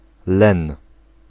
Ääntäminen
Synonyymit lin cultivé Ääntäminen France: IPA: [lə lɛ̃] Tuntematon aksentti: IPA: /lɛ̃/ Haettu sana löytyi näillä lähdekielillä: ranska Käännös Ääninäyte Substantiivit 1. len m Muut/tuntemattomat 2. len {m} Suku: m .